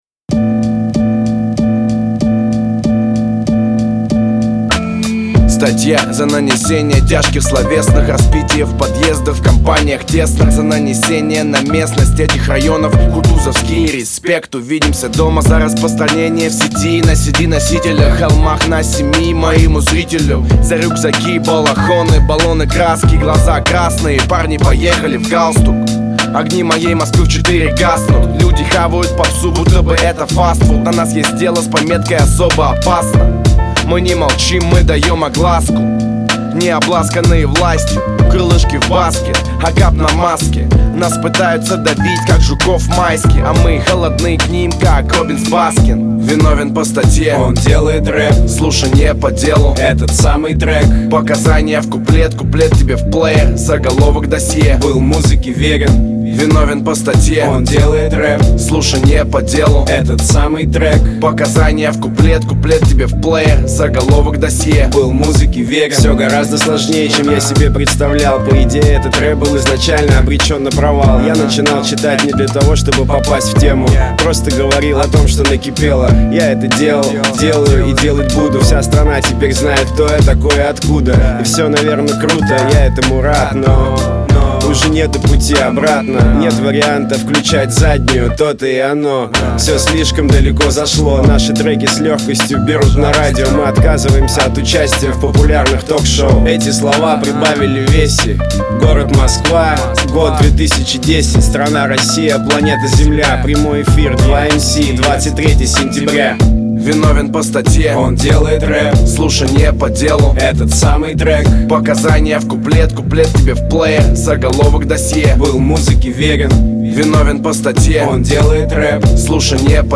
Жанр: Rap